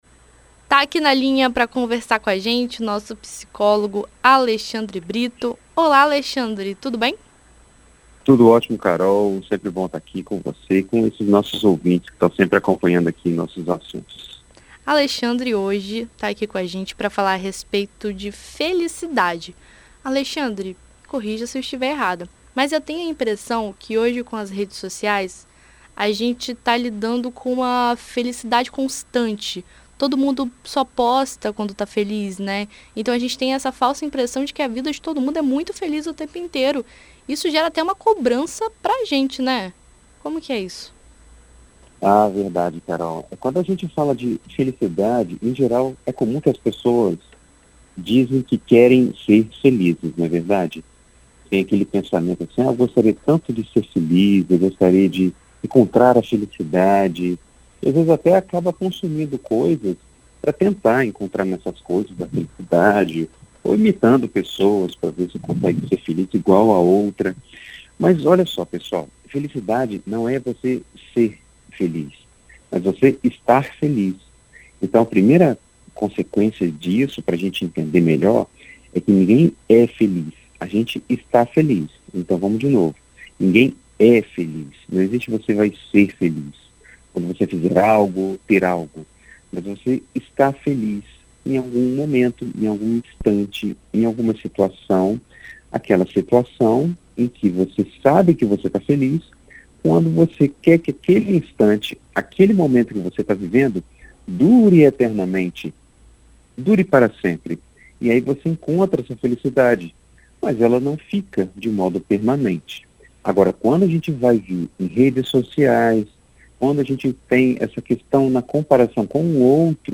Em um mundo imerso nas redes sociais, a felicidade se torna algo constante e massacrante na internet. Isso pode gerar alguns problemas psicológicos para aqueles que se baseiam nessa pseudofelicidade. Em entrevista à BandNews FM ES nesta segunda-feira (27),